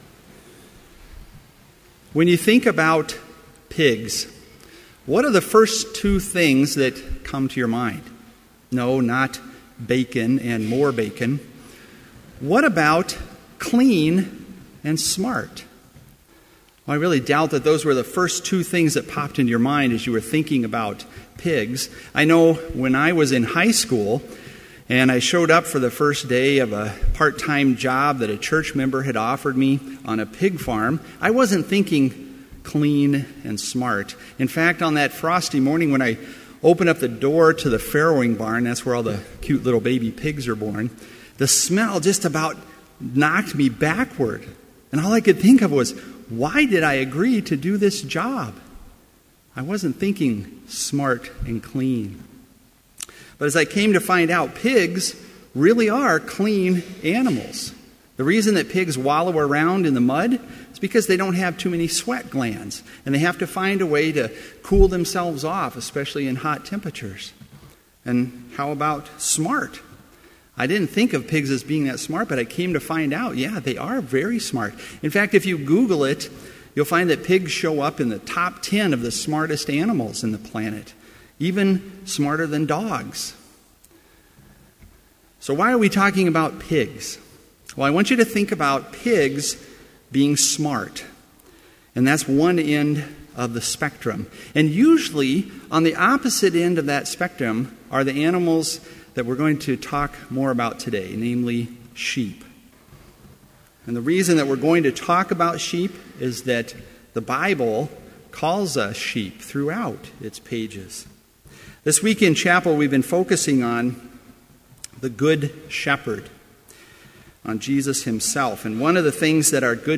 Complete Service
• Hymn 505, vv. 1, 3 & 5, O Lord, Who in Thy Love Divine
• Homily
This Chapel Service was held in Trinity Chapel at Bethany Lutheran College on Wednesday, May 7, 2014, at 10 a.m. Page and hymn numbers are from the Evangelical Lutheran Hymnary.